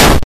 explosion.ogg